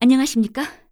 cleric_f_voc_social_01.wav